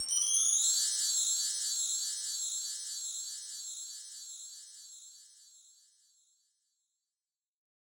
Boss Dr Pad Sample Pack_Chime-Long.wav